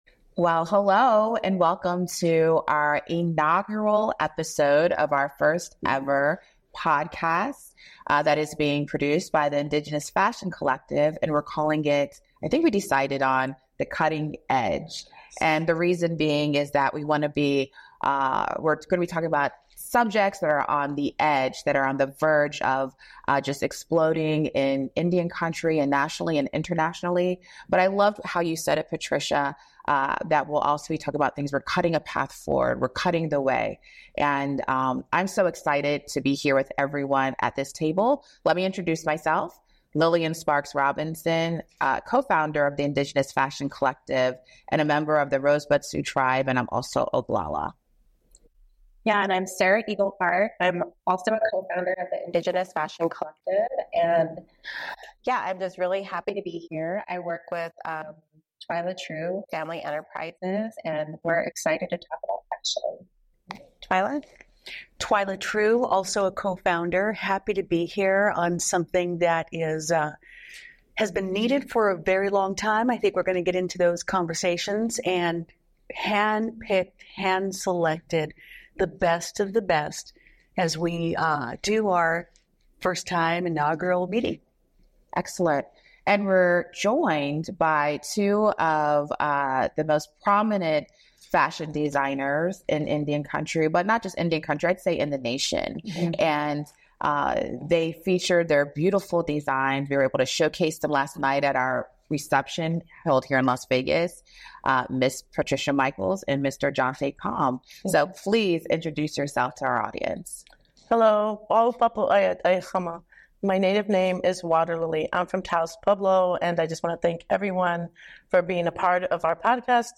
the very first conversation